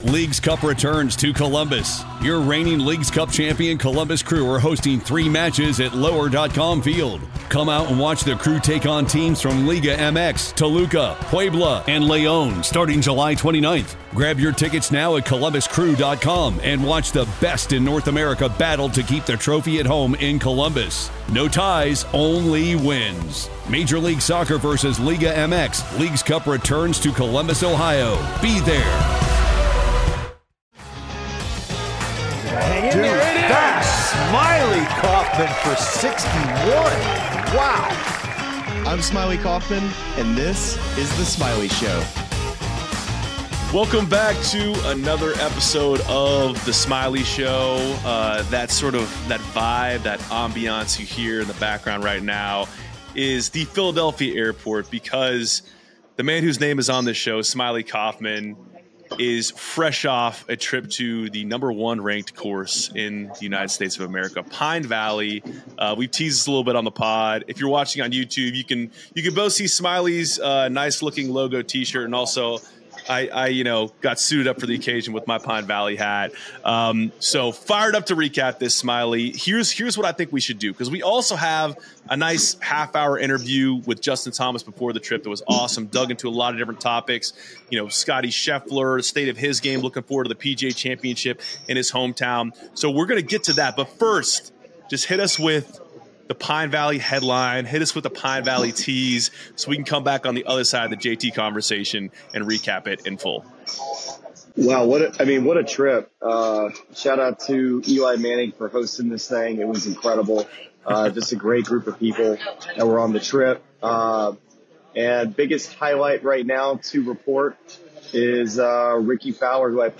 First, Smylie Kaufman is joined by Justin Thomas - ahead of their trip to Pine Valley - to discuss his recent caddie change, Scottie Scheffler's dominance, and looking forward to the PGA Championship in his hometown of Louisville at Valhalla.